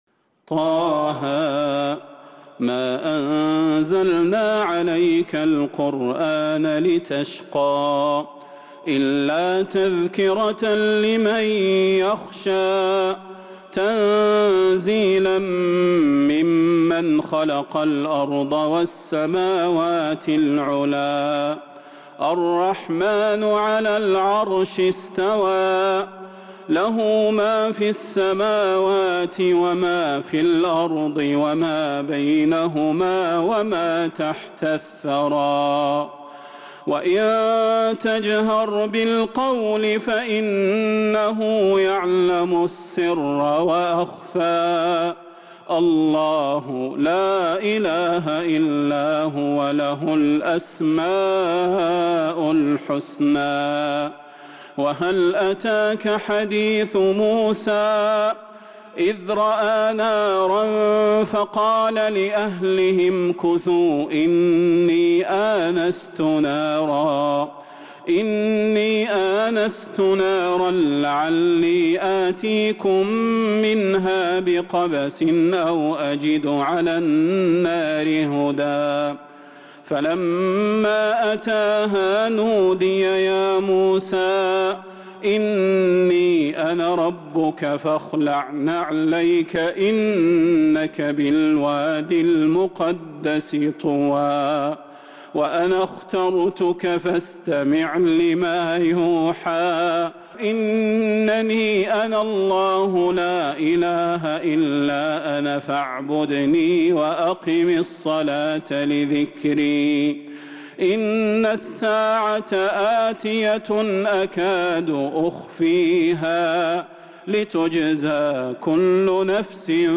سورة طه كاملة من تراويح الحرم النبوي 1442هـ > مصحف تراويح الحرم النبوي عام 1442هـ > المصحف - تلاوات الحرمين